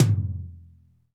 TOM RLTOM0KR.wav